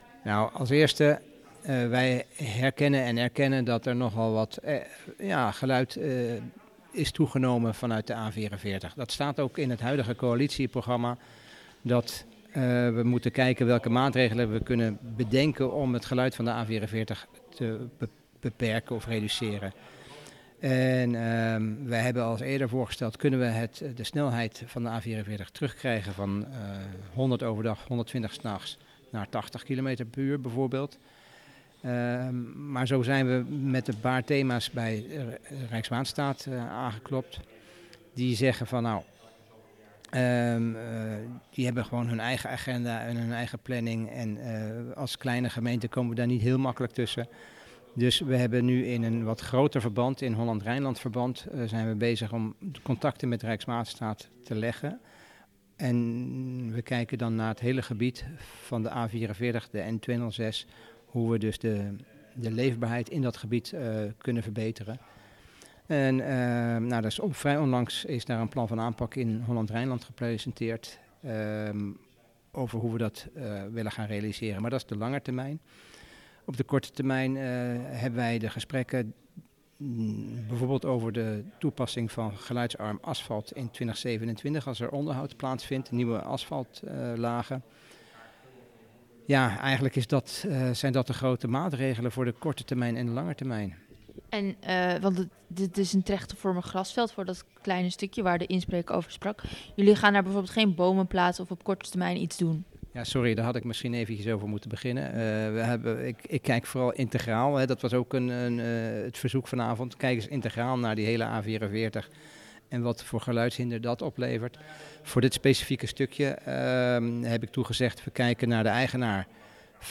Verslaggever
spreekt met Wethouder Kees Oudendijk over de problemen rondom de A44.